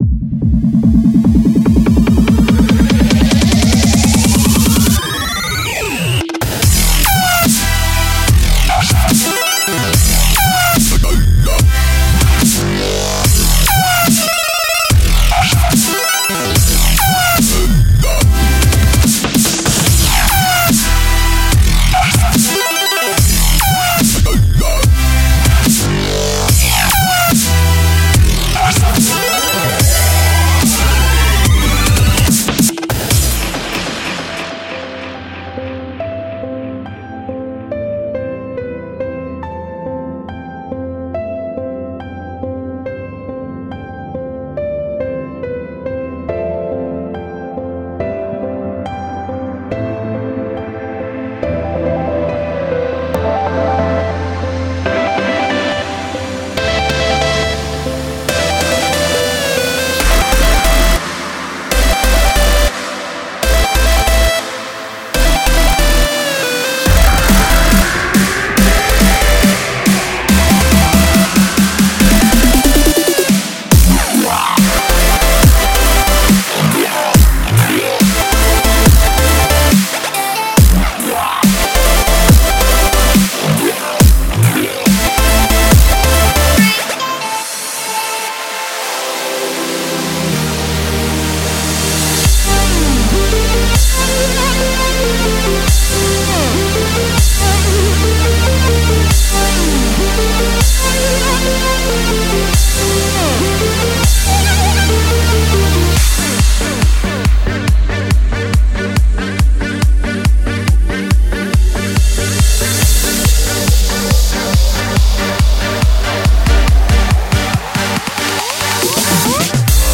3. Dubstep
超过30个低音循环，鼓循环，主音，音乐循环和SFX。所有声音均通过44立体声Wav以16位显示。
*鼓和低音
*嘻哈/陷阱